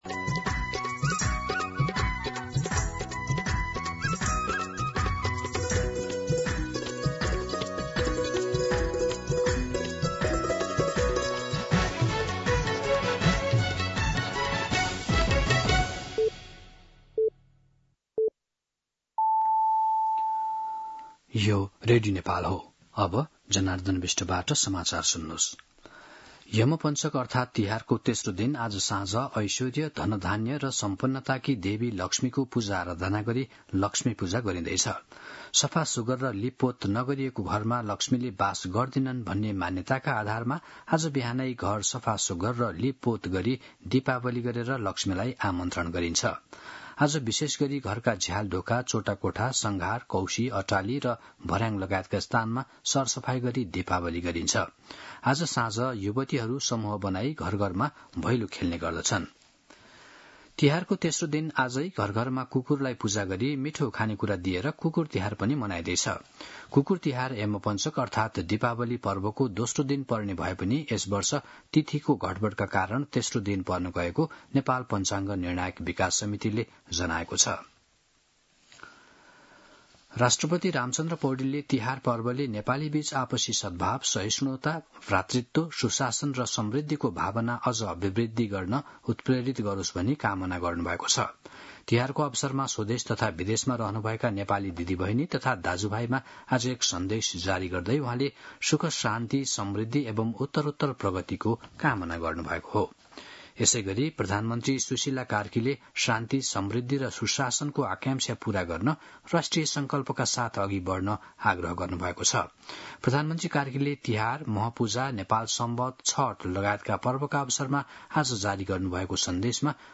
मध्यान्ह १२ बजेको नेपाली समाचार : ३ कार्तिक , २०८२
12-pm-Nepaki-News-1.mp3